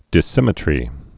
(dĭs-sĭmĭ-trē)